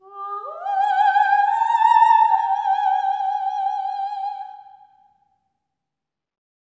OPERATIC07-R.wav